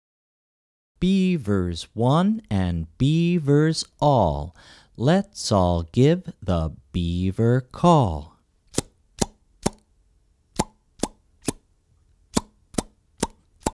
Fingerplay for Young Children